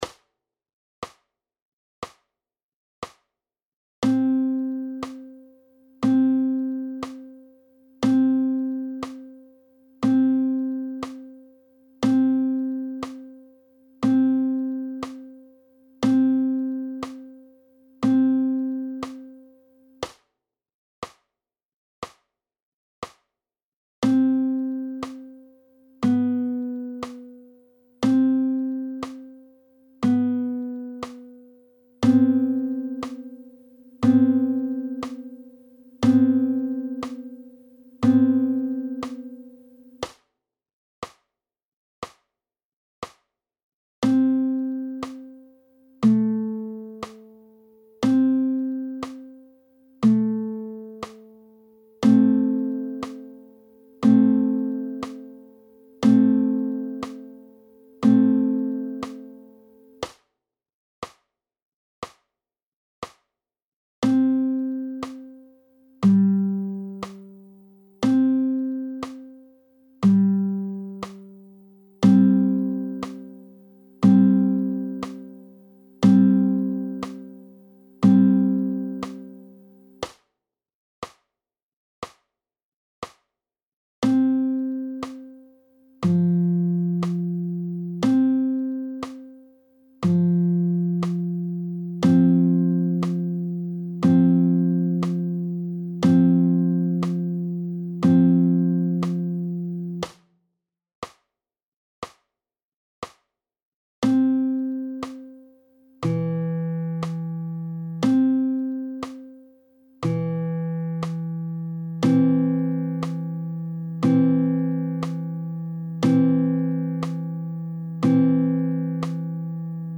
3.) Intervalle (kl./rein) der C-Dur-TL in I. Lage, vertikal, abwärts: PDF